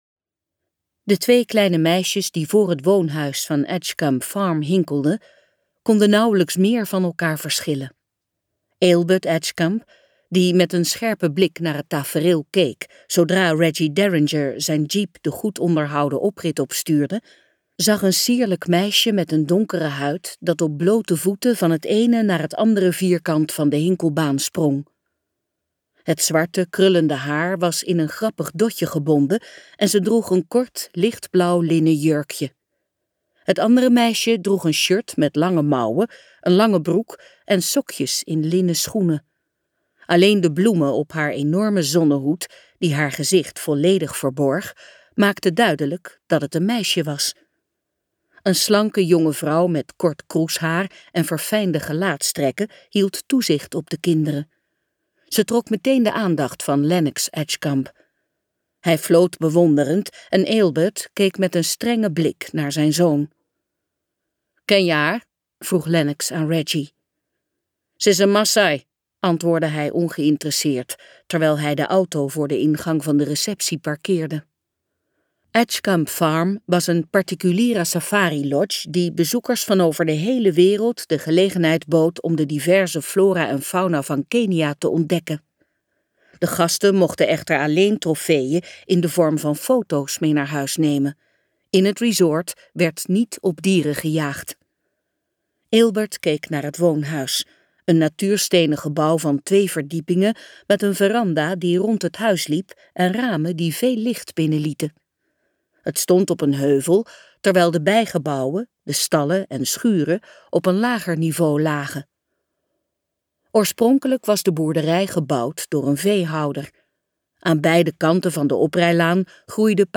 Uitgeverij De Fontein | Vriendinnen voor het leven luisterboek